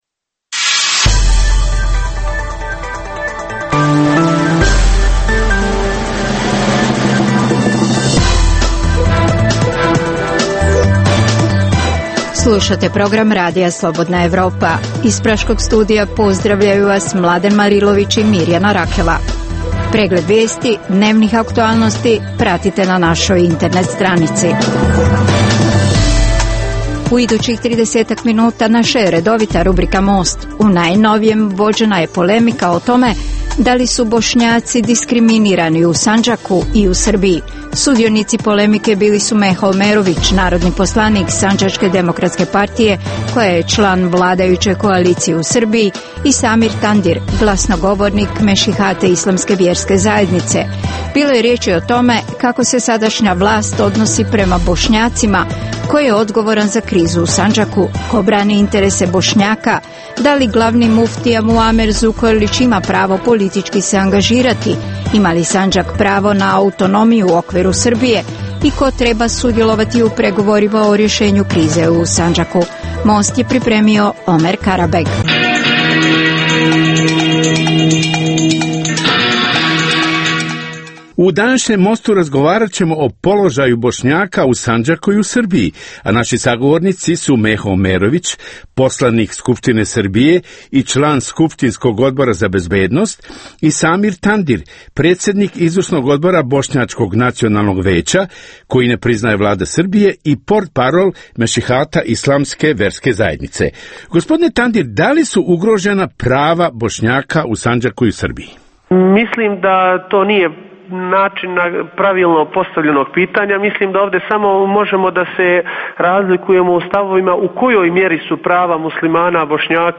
U ovom Mostu vođena je polemika o tome da li su Bošnjaci diskriminarini u Sandžaku i u Srbiji.